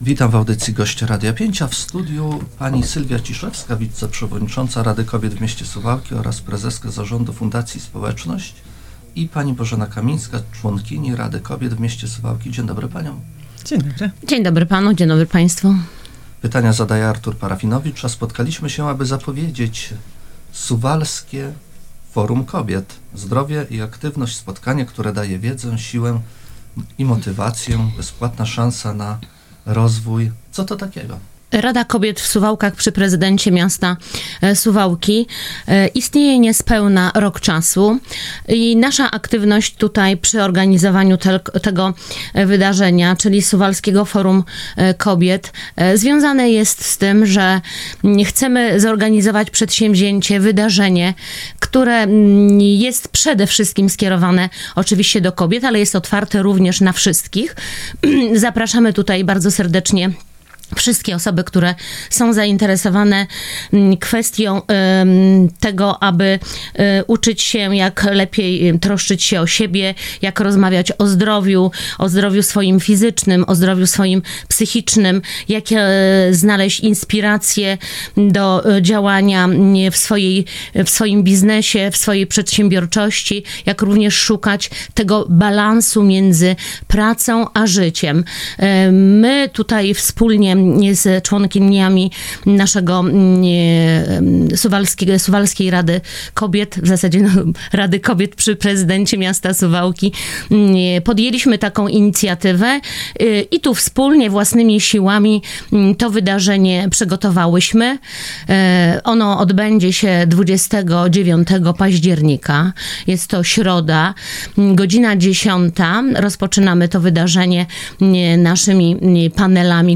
Na wydarzenie zapraszały w piątek (10 października) w Radiu 5